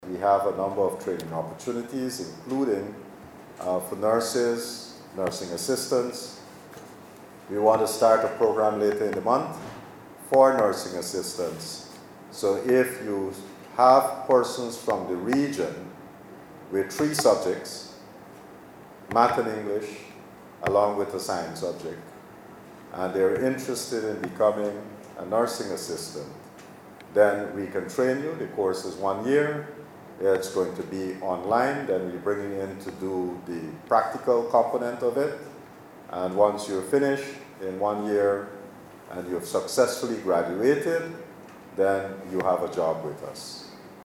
In his address, Dr. Anthony also announced forthcoming training opportunities within the health sector for the year.